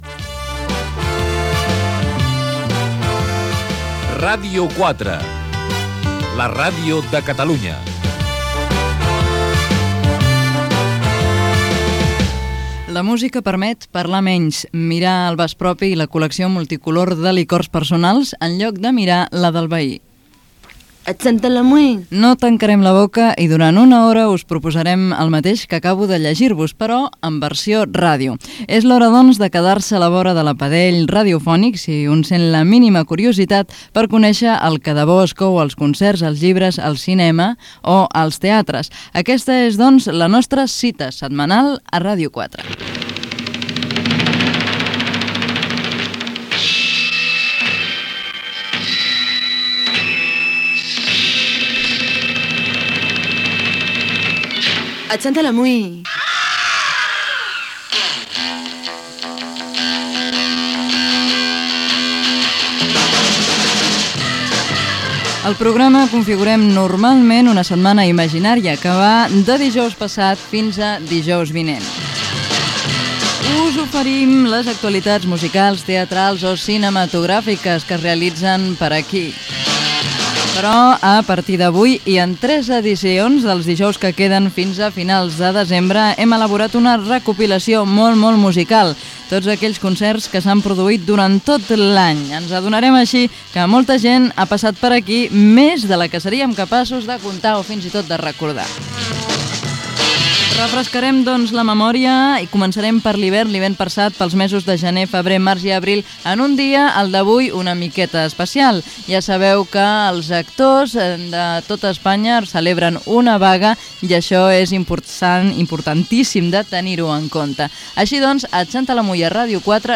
Indicatiu de la ràdio, frase, indicatiu del programa, presentació, careta, espai dedicat al repàs dels festivals musicals de l'any i a alguns concerts.